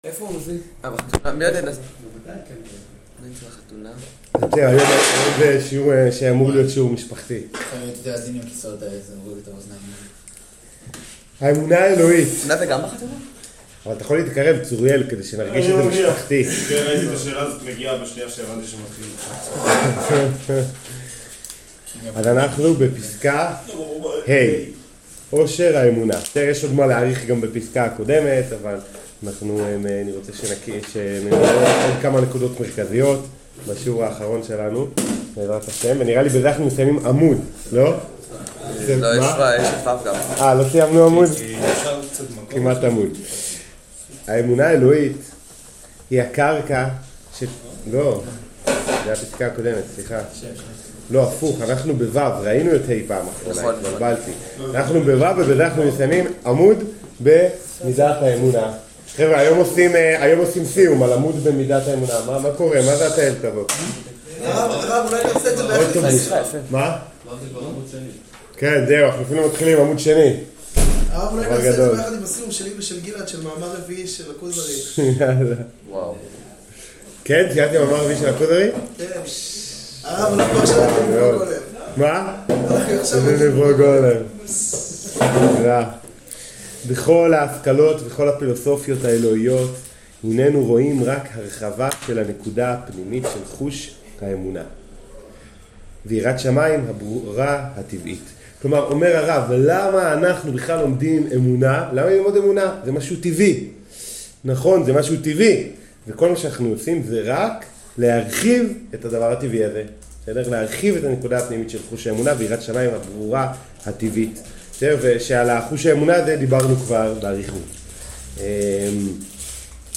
שיעור פסקה ו